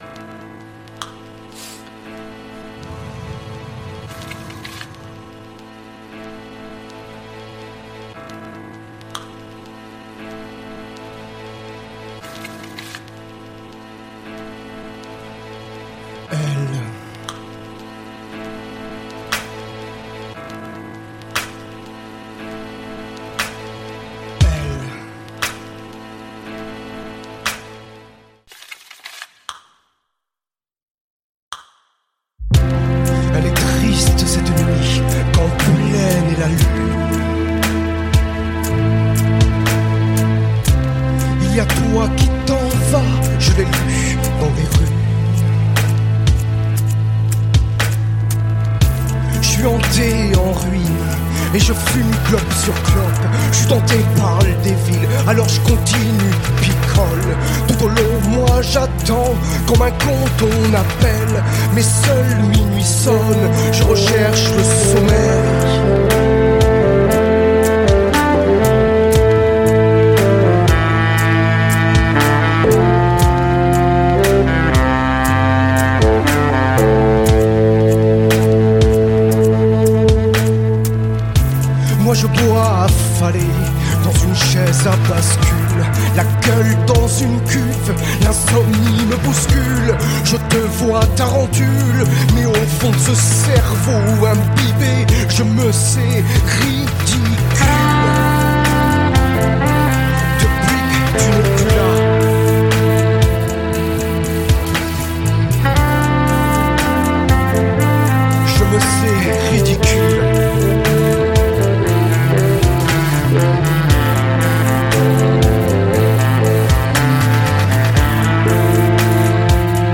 концептуальный хип-хоп альбом
с нетипичной для этого жанра музыкой